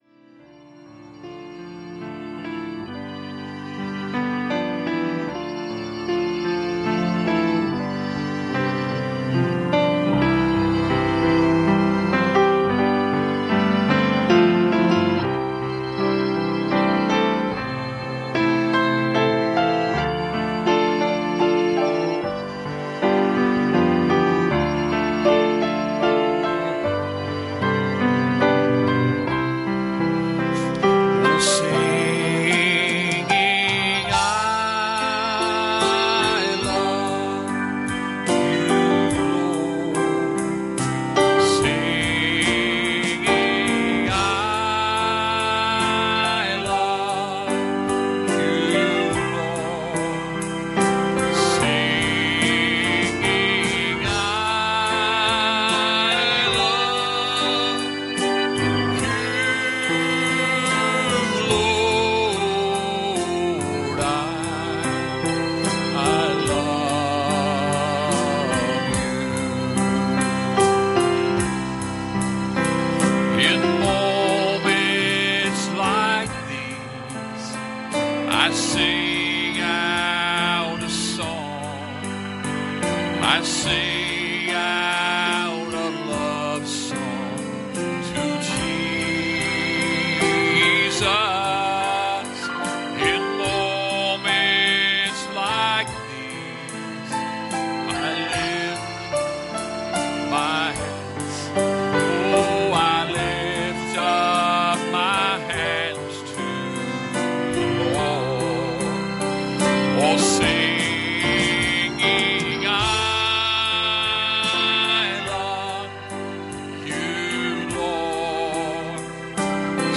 Passage: Revelation 2:18-19 Service Type: Sunday Evening